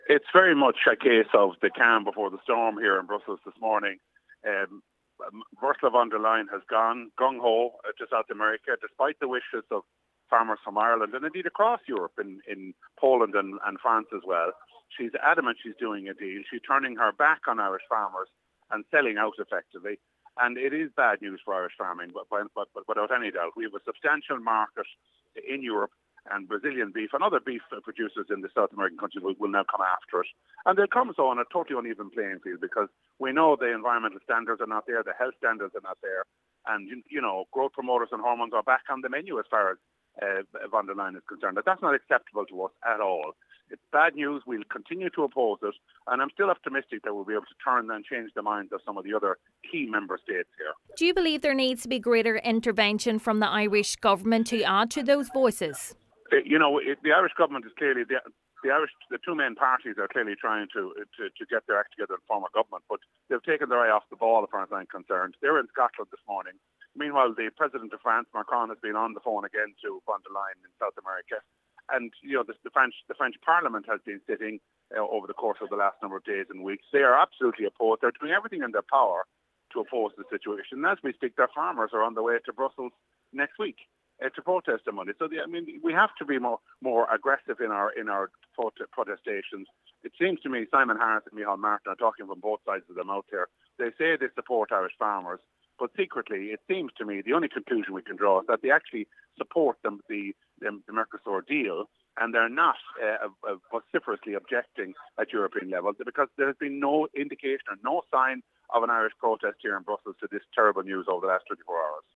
Ciaran Mullooly says the silence from the Irish Government is deafening: